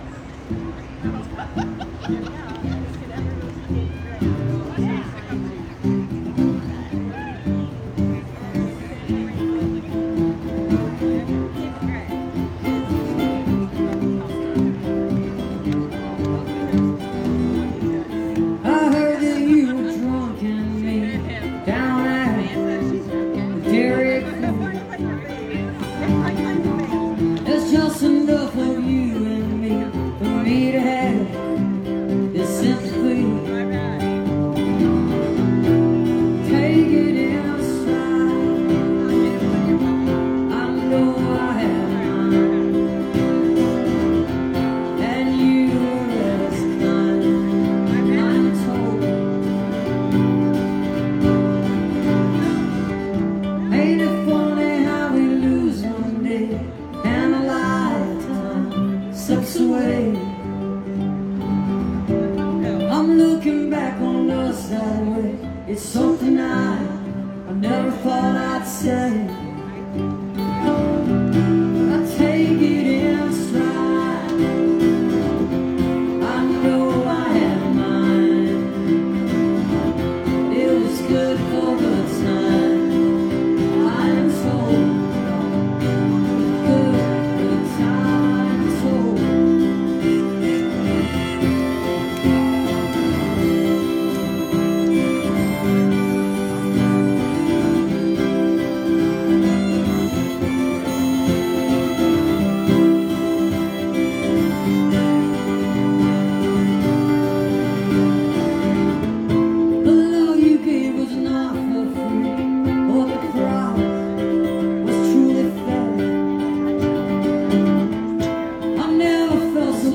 (captured from the facebook livestream)